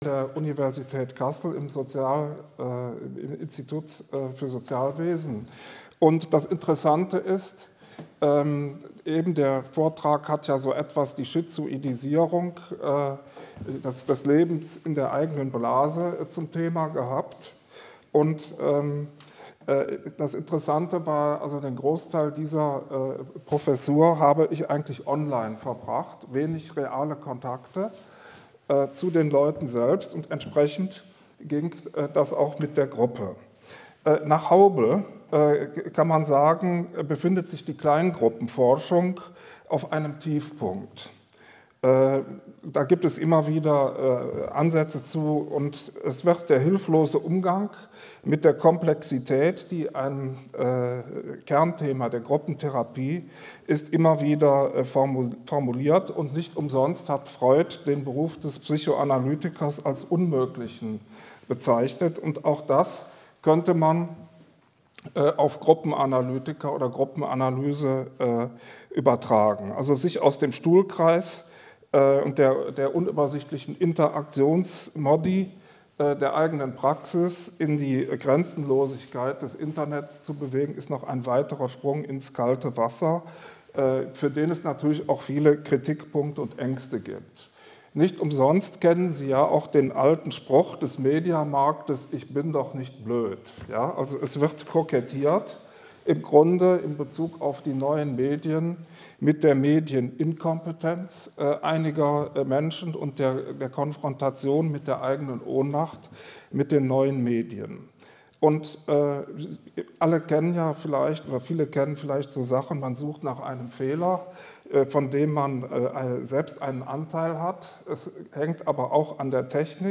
Vortrag 6.5.2023, Siegburg: 28. Rheinische Allgemeine PSYCHOtherapietagung - Psychotherapie nach der Zeitenwende